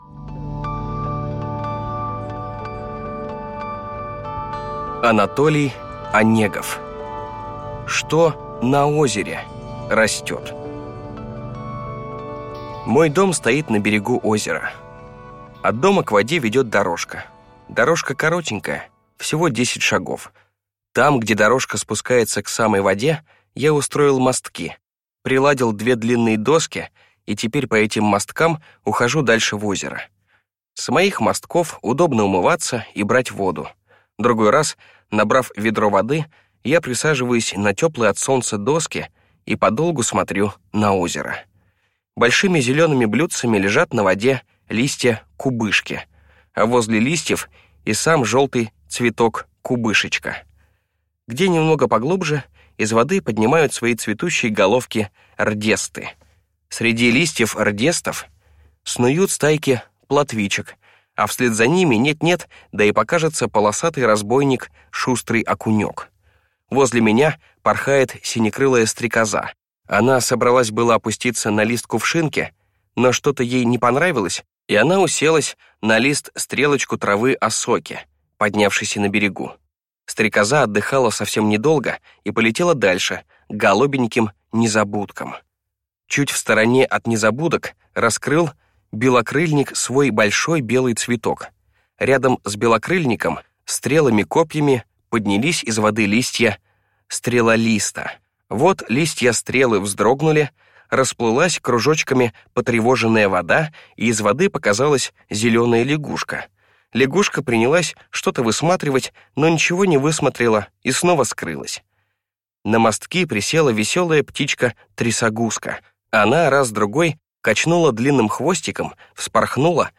Аудиокнига Что на озере растёт?